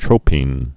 (trōpēn, -pĭn) also tro·pin (-pĭn)